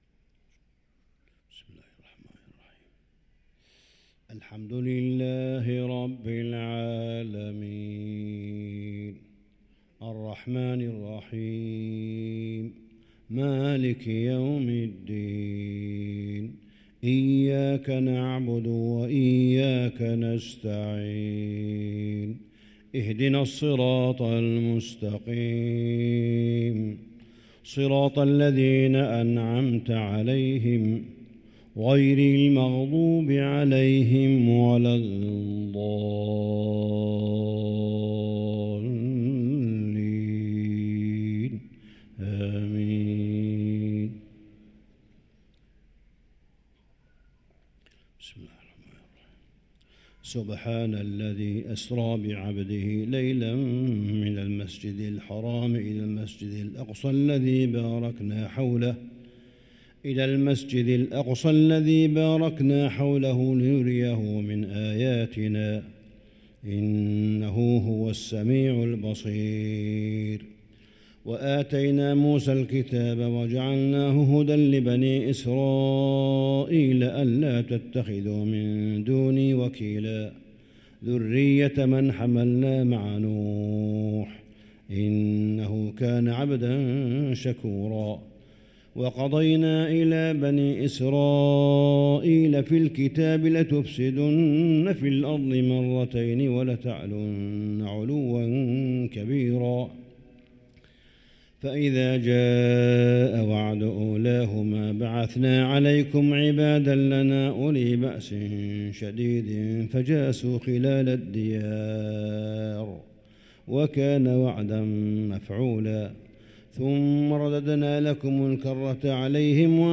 صلاة الفجر للقارئ صالح بن حميد 23 صفر 1445 هـ
تِلَاوَات الْحَرَمَيْن .